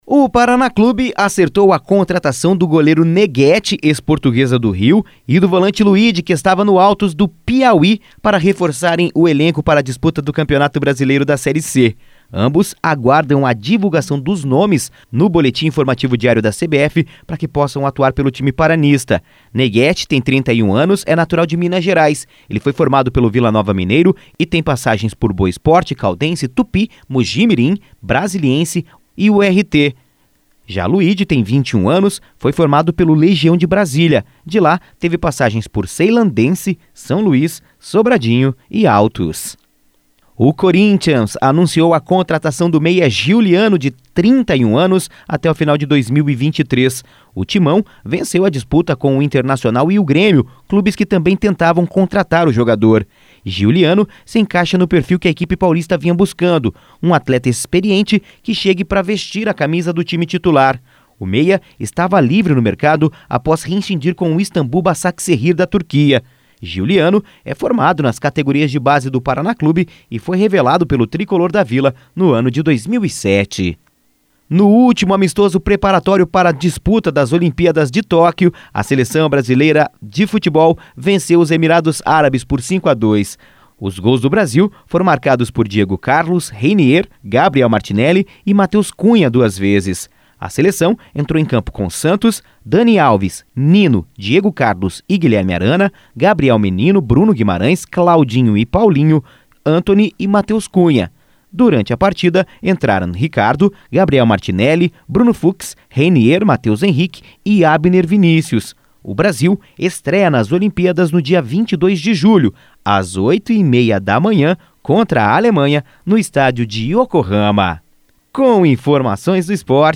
Giro Esportivo (SEM TRILHA)